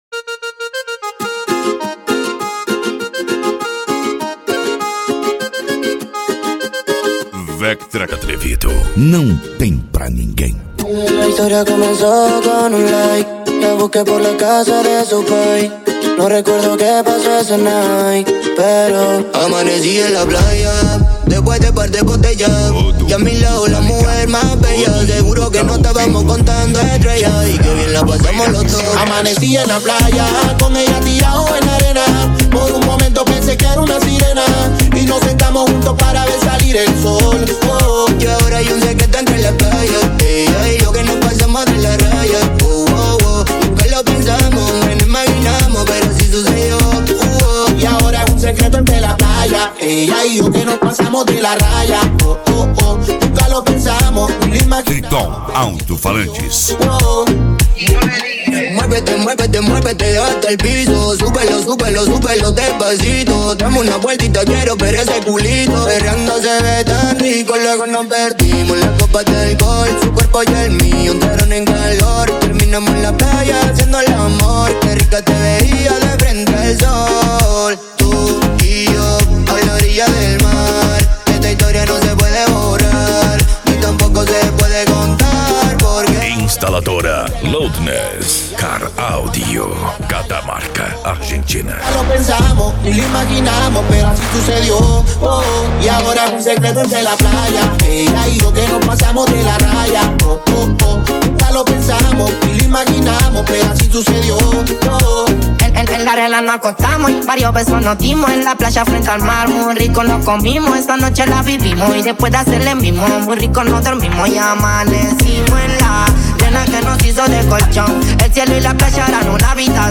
Bass
Cumbia
Musica Electronica
Hip Hop
Remix